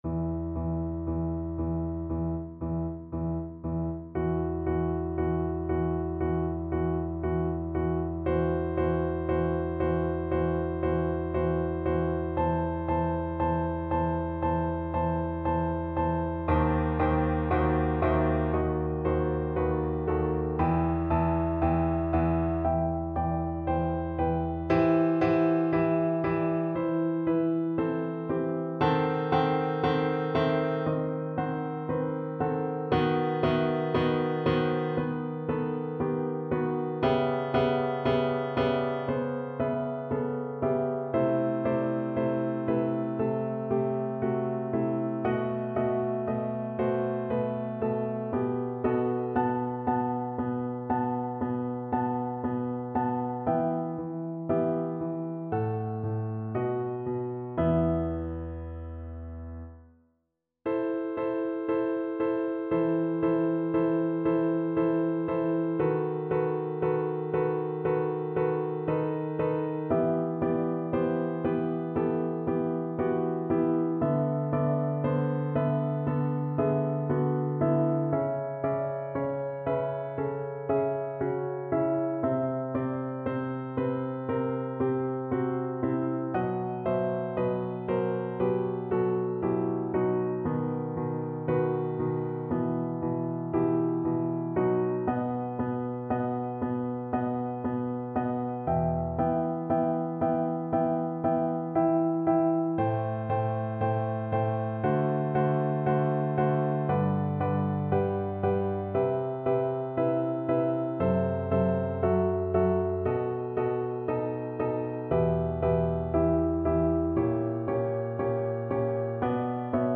Violin version
Piano Playalong MP3